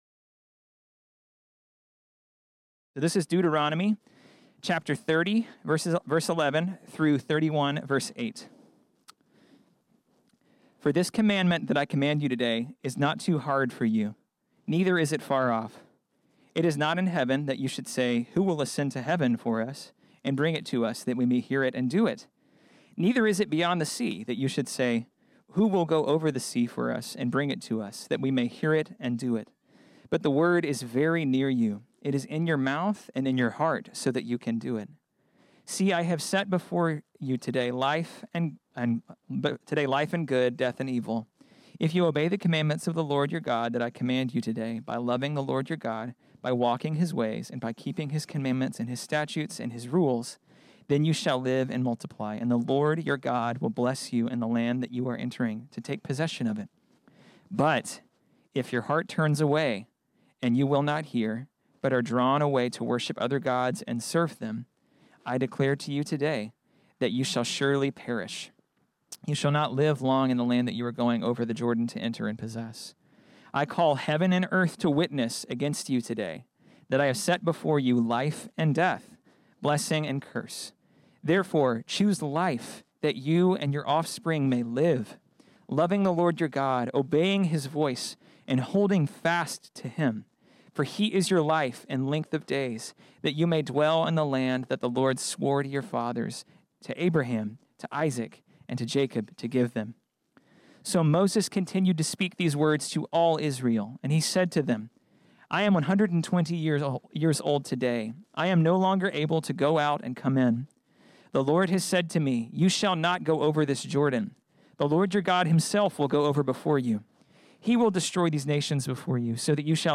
This sermon was originally preached on Sunday, September 6, 2020.